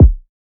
Teck-kick (too late).wav